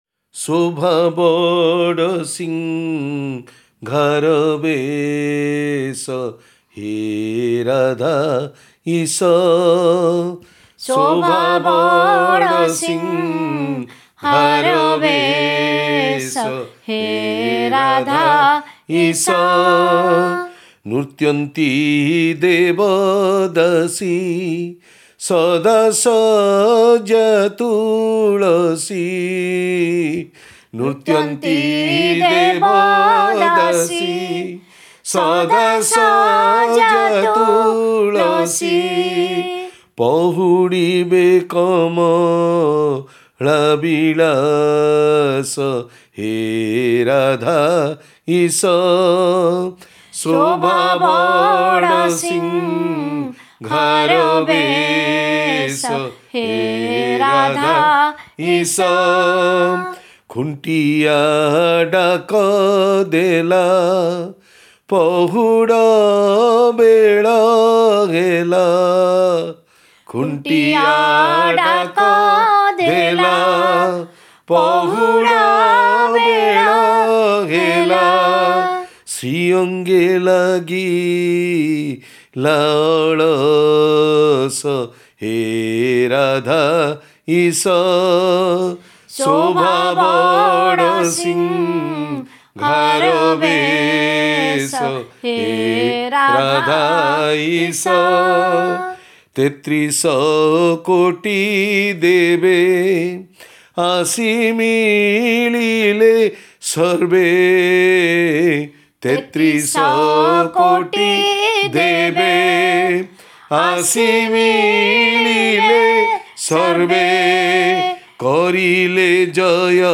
Song in praise of the divine graceful beauty of Lord Jagannātha’s Baḍa Siṃhāra Veśa (Great Flowery Attire) when the Lord is completely adorned with beautiful garlands in the Śrī Jagannātha Temple in Puri, every night, before the doors of the Inner Sanctum are closed for the Lord to sleep.